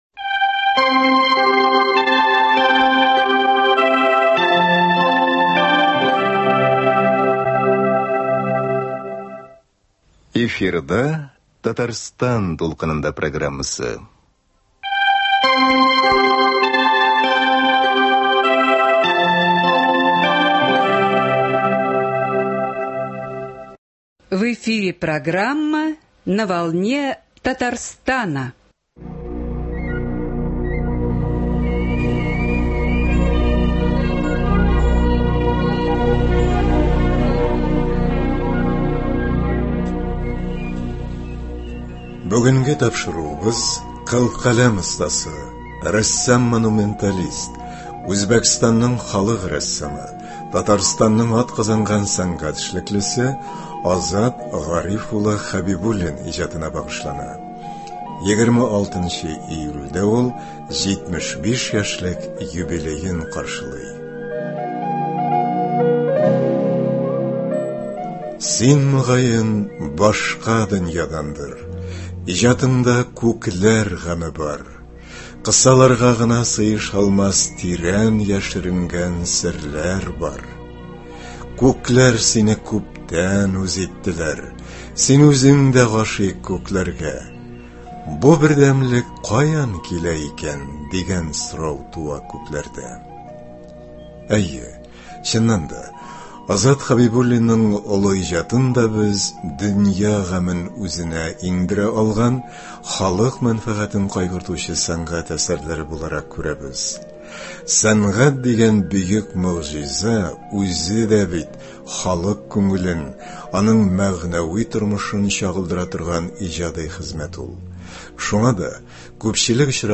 Студиябез кунагы Үзбәкстанның халык рәссамы
үз шигырьләрен укый.